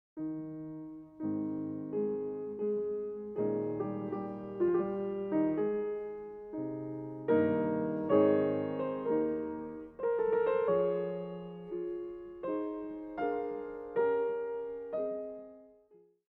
Movement 1: Andante con variazioni